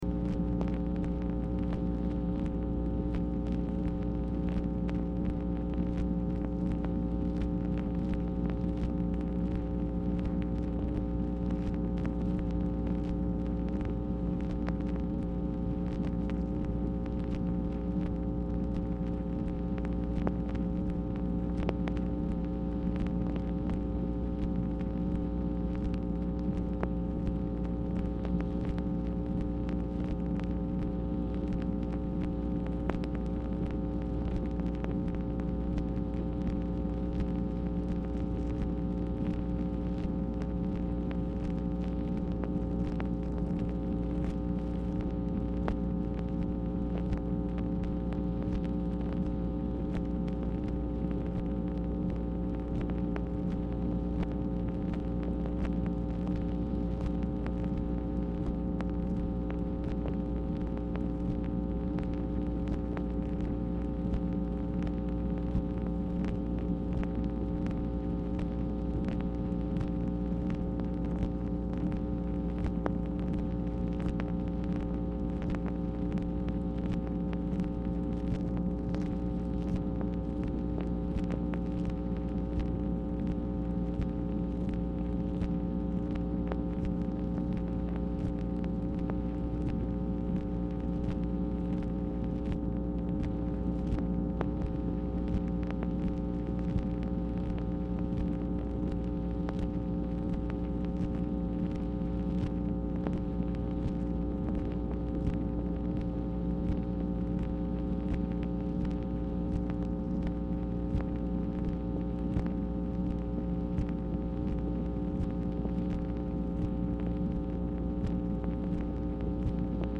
Telephone conversation # 10907, sound recording, MACHINE NOISE, 10/1/1966, time unknown | Discover LBJ
Dictation belt